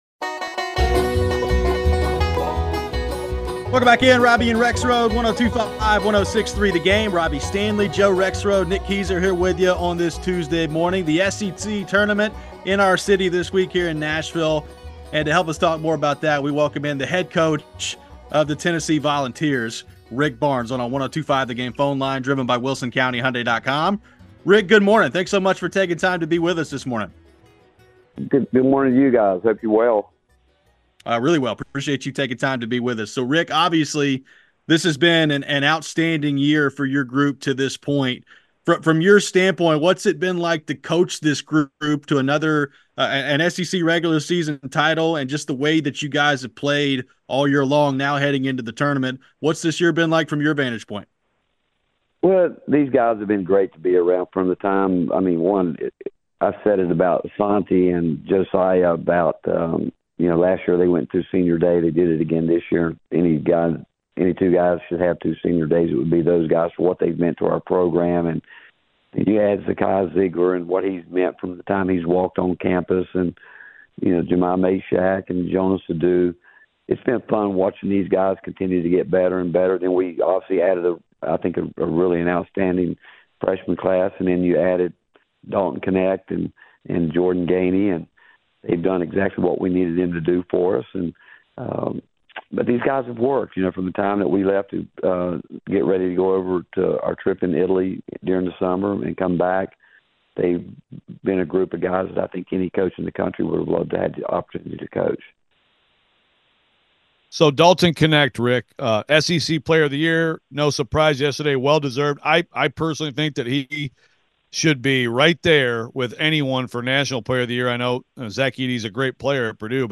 Tennessee men's basketball coach Rick Barnes joined the show before the SEC Tournament starts. What does he make of his team to this point going into the back half of March?